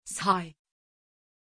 Aussprache von Shay
pronunciation-shay-tr.mp3